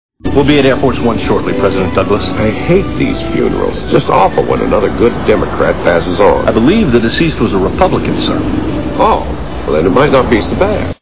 Listen to a dialogue between a secret service agent and the President.